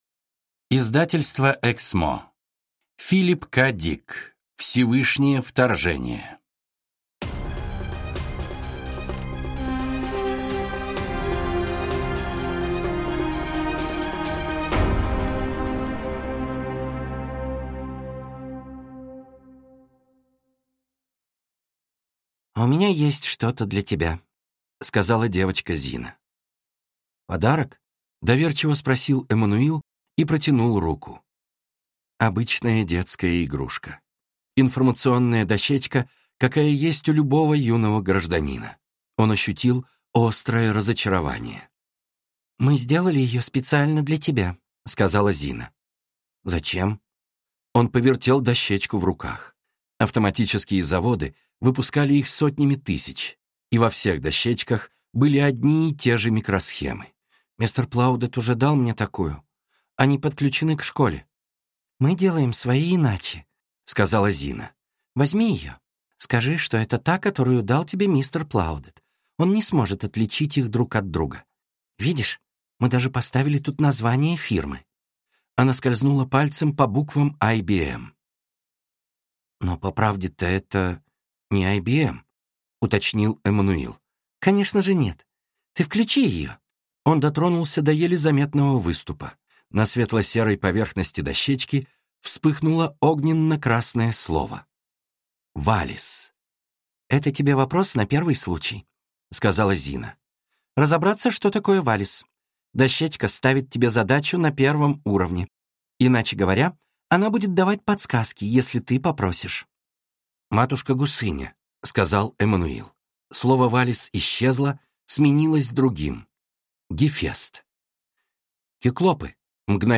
Аудиокнига Всевышнее вторжение | Библиотека аудиокниг